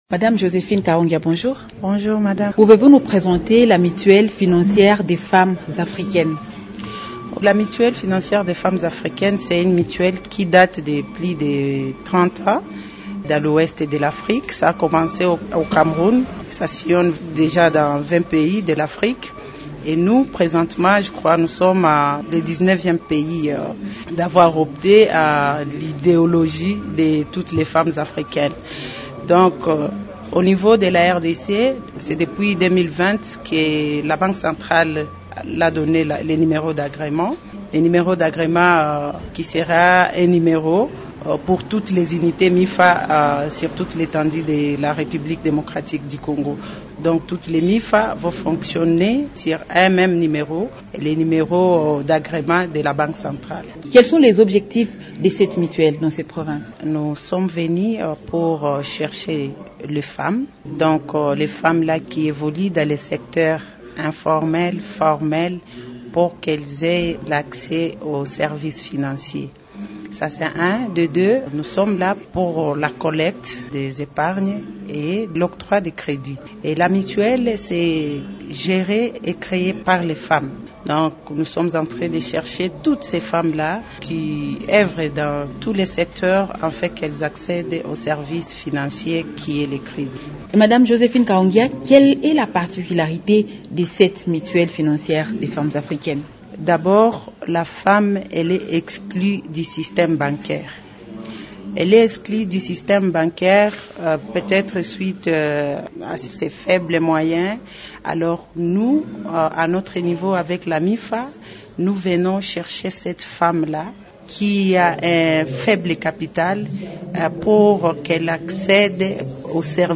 Invitée de Radio Okapi